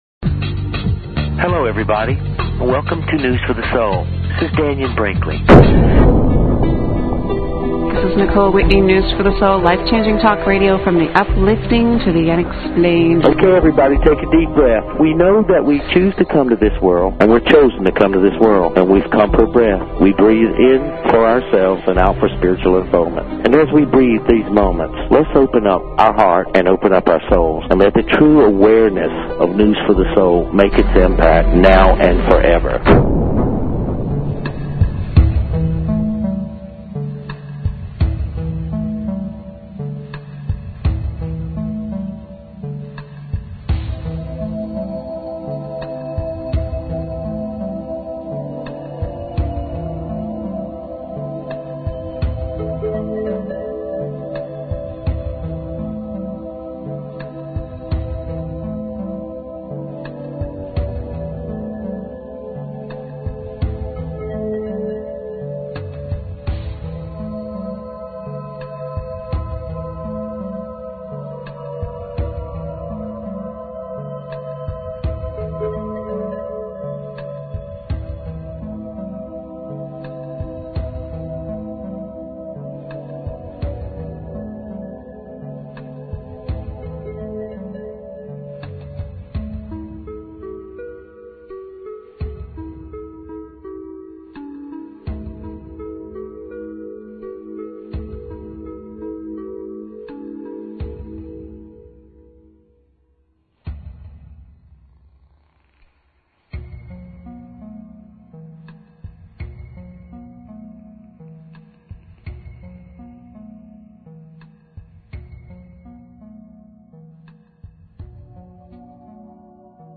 Talk Show Episode, Audio Podcast, News_for_the_Soul and Courtesy of BBS Radio on , show guests , about , categorized as